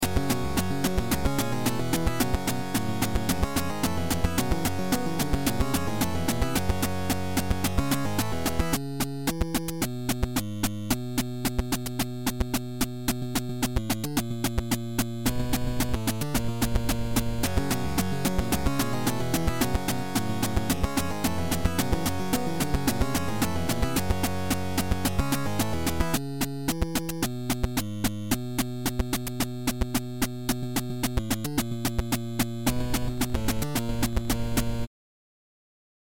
There is an occasional sour bass note, but otherwise I’m happy with the progress so far.
The melodies, parts, and arrangements were all formed procedurally.